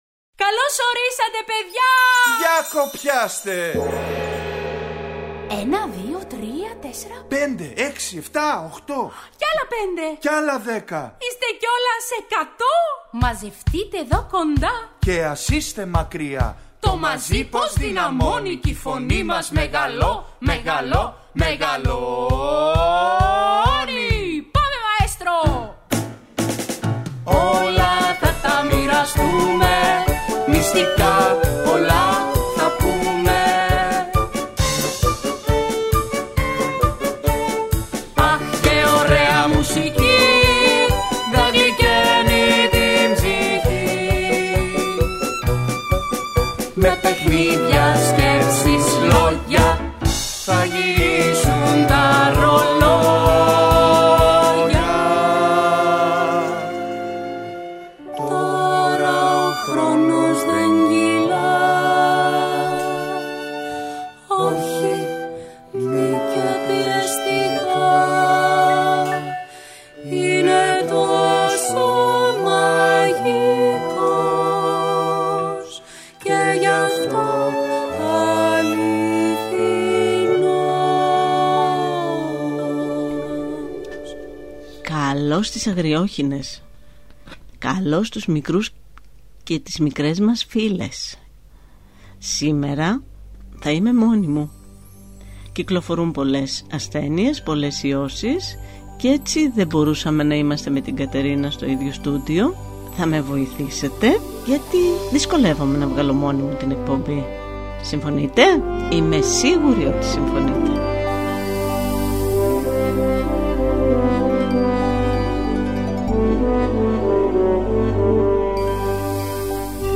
Ακούστε στην παιδική εκπομπή ‘’Οι Αγριόχηνες’’ το παραμύθι « Η πινεζοβροχή »του Ευγένιου Τριβιζά.